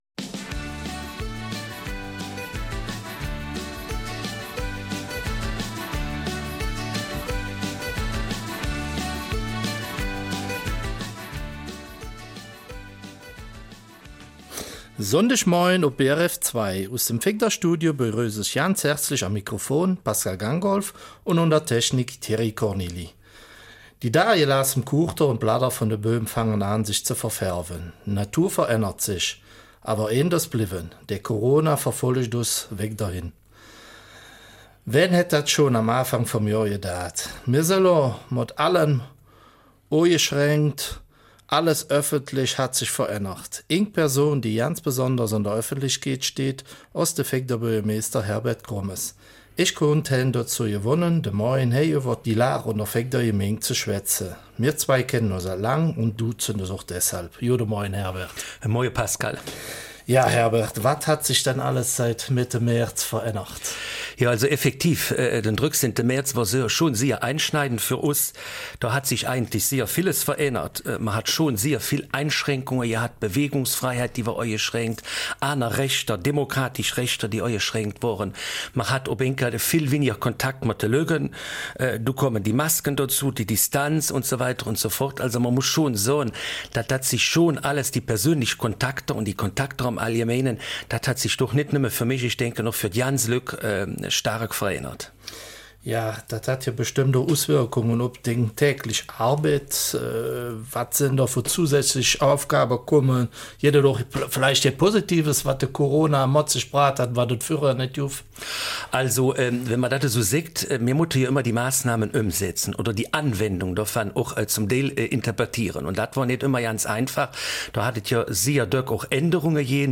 Eifeler Mundart: Neues aus St.Vith 12.